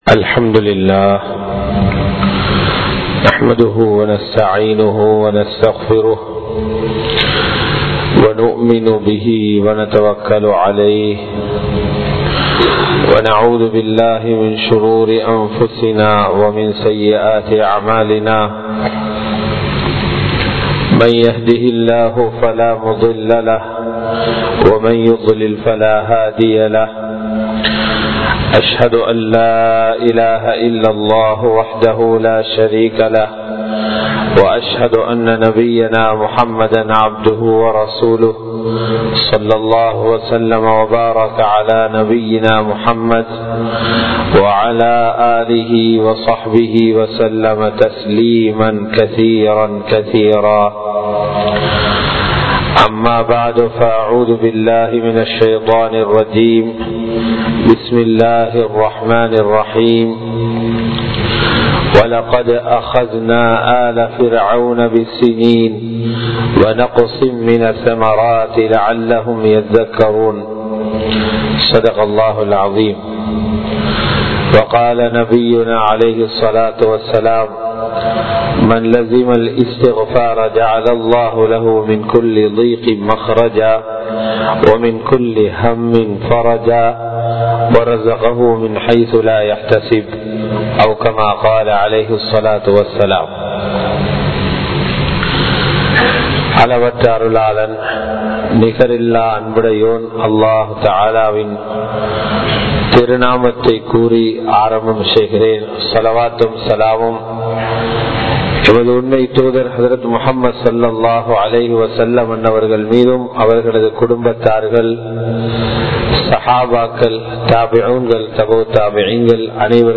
வாழ்க்கையில் நெருக்கடியா? | Audio Bayans | All Ceylon Muslim Youth Community | Addalaichenai
Muhideen (Markaz) Jumua Masjith